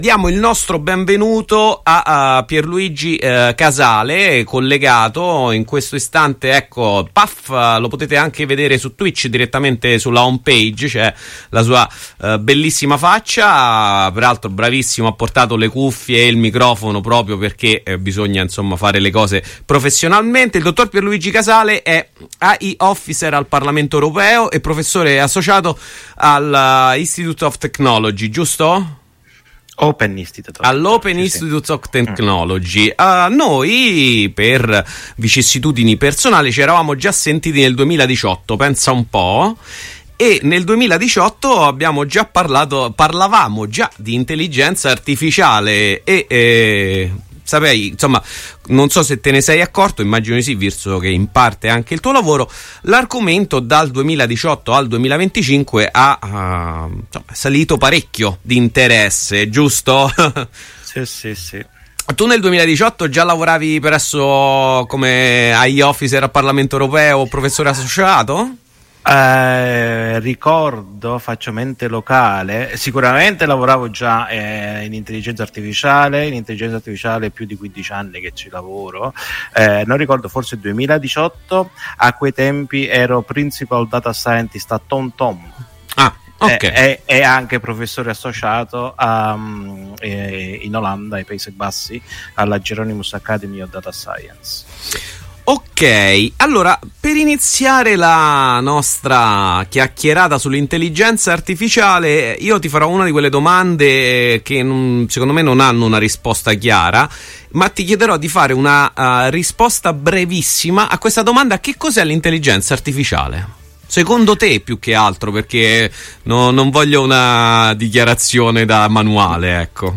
Abbiamo parlato di Intelligenza Artificiale, di cosa sia, come funziona, dei suoi utilizzi attuali e futuribili. Delle problematiche etiche legate alle AI, ambientali, delle norme e leggi che regolano il suo sviluppo e proteggono anche i suoi utenti da possibili usi non del tutto positivi. Riascolta l’intervista.